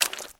High Quality Footsteps
STEPS Swamp, Walk 18.wav